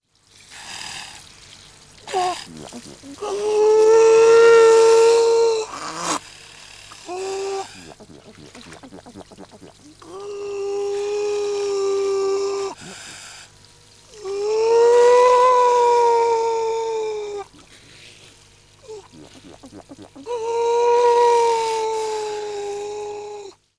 Kegelrobbe
Hier können Sie sich die Stimme der Kegelrobbe anhören: Heulleute eines Kegelrobbe Weibchens
823-kegelrobbe_weibchen_heullaute-soundarchiv.com_.mp3